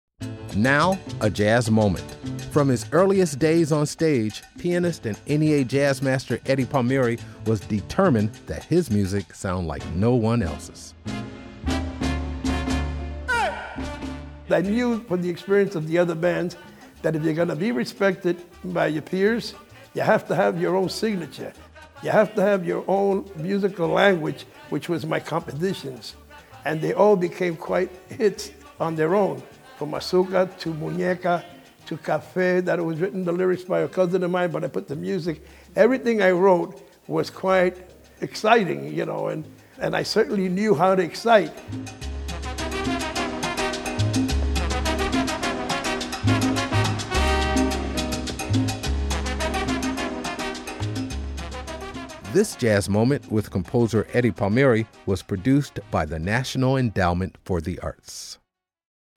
Excerpt of "Azucar” composed and performed by by Eddie Palmieri, used courtesy of FANIA music and by permission of The Palmieri Organization (BMI).